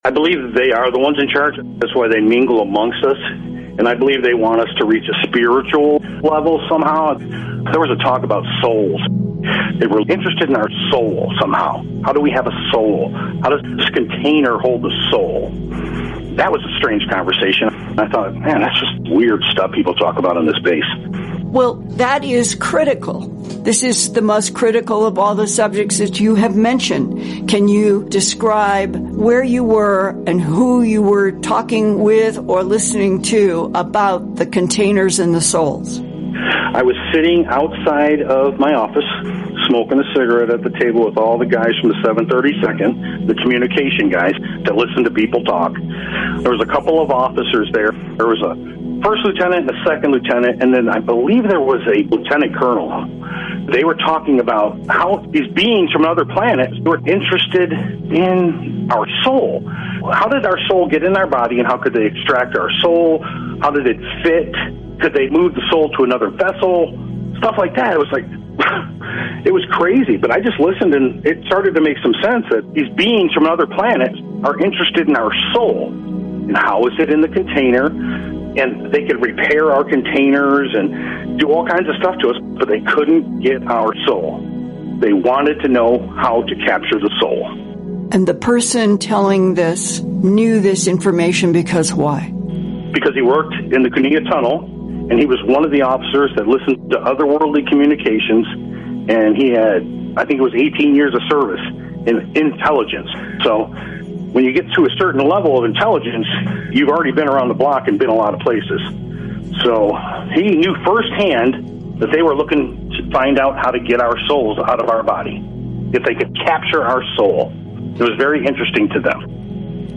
rebroadcast interview